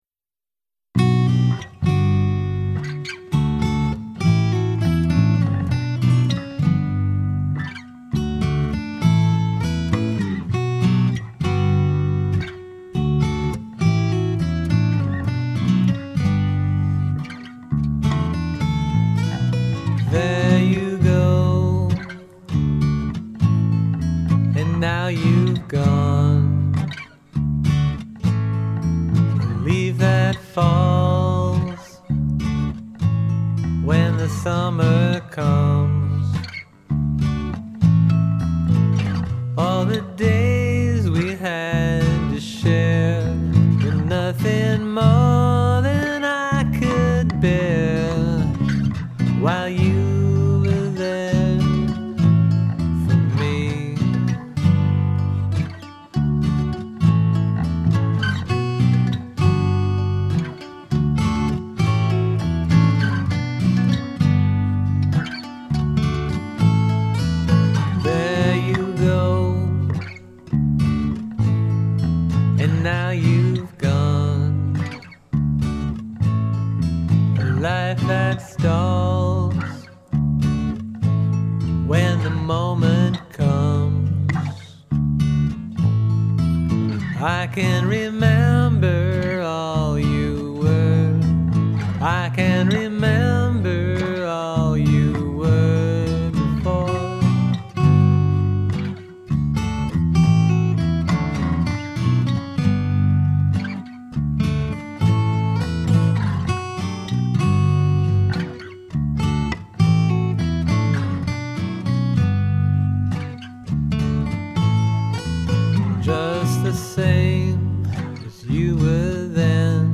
vocals, guitar, mandolin, ukulele, bass, percussion